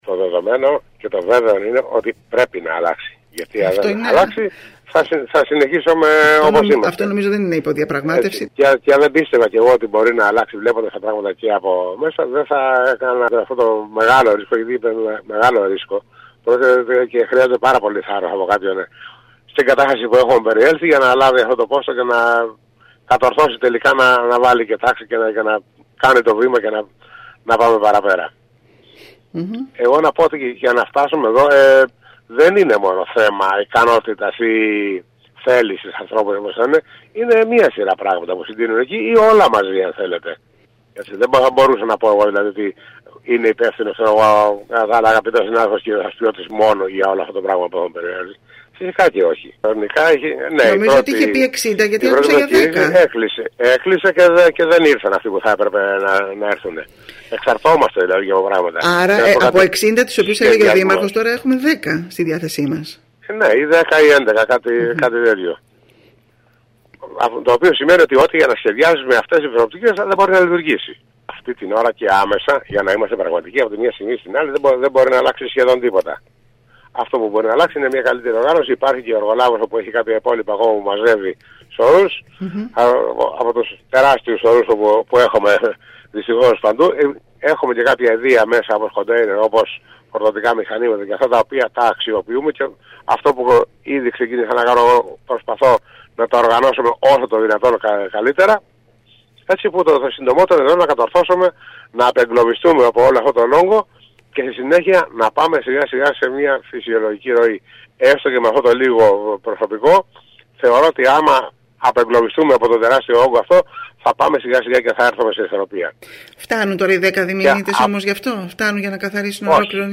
Με μεγάλη δυσκολία εξελίσσεται η αποκομιδή των απορριμμάτων σε όλη την Κέρκυρα ενώ μιλώντας σήμερα στην ΕΡΤ ο νέος αντιδήμαρχος καθαριότητας Σπύρος Καλούδης επεσήμανε ότι αυτή δεν εξαρτάται μόνο από το δήμο αλλά και από άλλους παράγοντες. Όπως είπε συγκεκριμένα, ο δήμος δεν έχει το απαιτούμενο προσωπικό και στην  προκήρυξη για την πρόσληψη 60 διμηνιτών ανταποκρίθηκαν μόνο 10 άτομα.